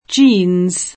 vai all'elenco alfabetico delle voci ingrandisci il carattere 100% rimpicciolisci il carattere stampa invia tramite posta elettronica codividi su Facebook jeans [ingl. J& in @ ; italianizz. J in S ] → blue jeans